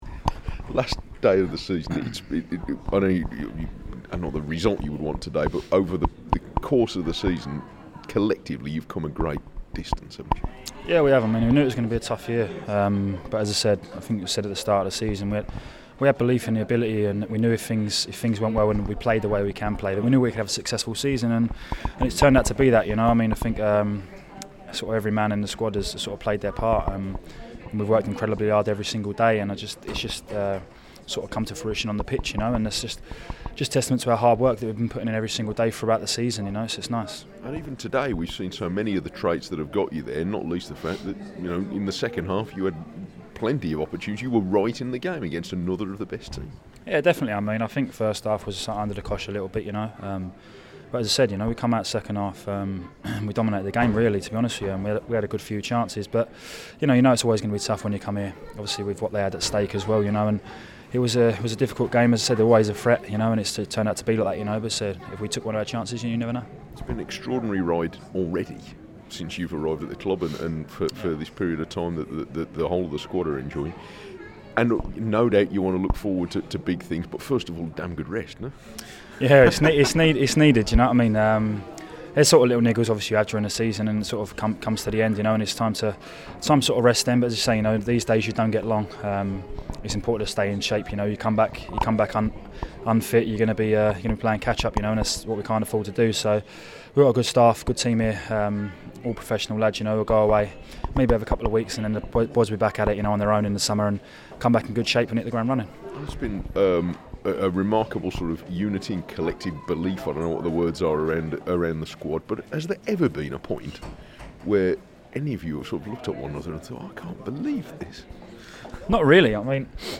The Wolves centre back talks to BBC WM after their final day trip to Liverpool.